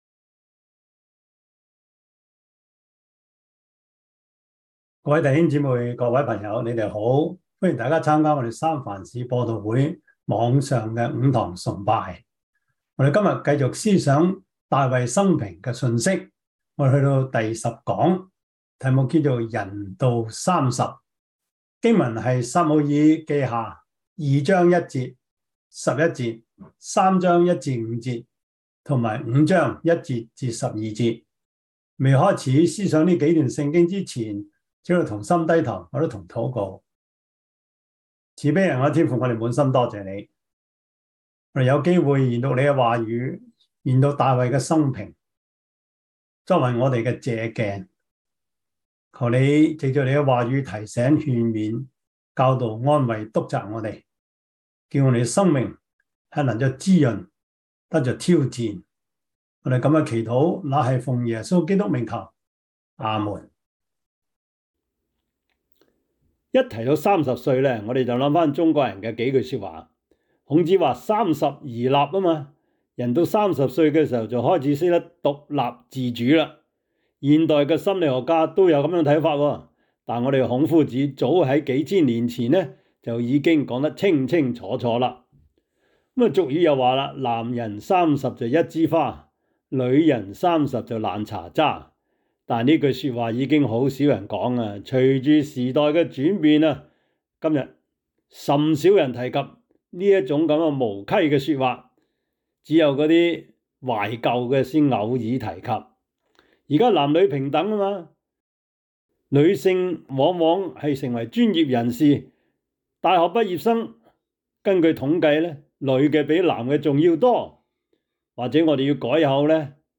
5:1-12 Service Type: 主日崇拜 撒母耳記下 2:1&11 Chinese Union Version
Topics: 主日證道 « 站起來 – 丟掉包袱, 重建生活 問責 »